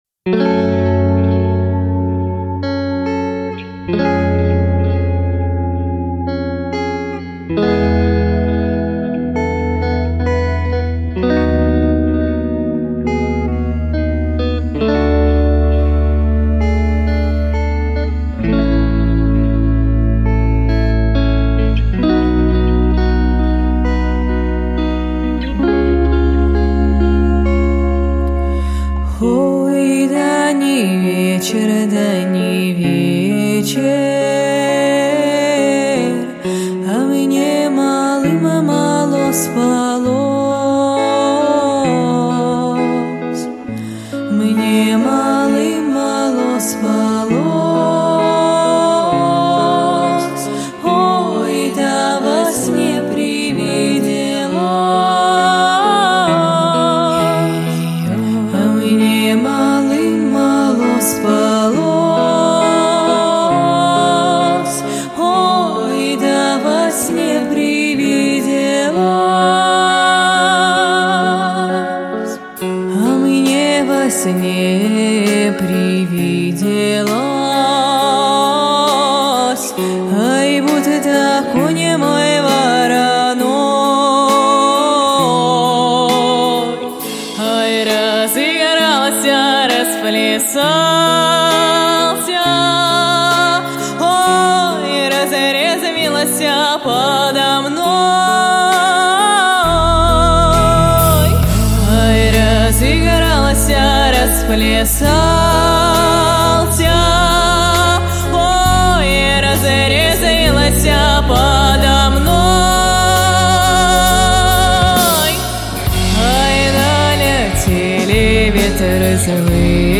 • Жанр: Детские песни
народный мотив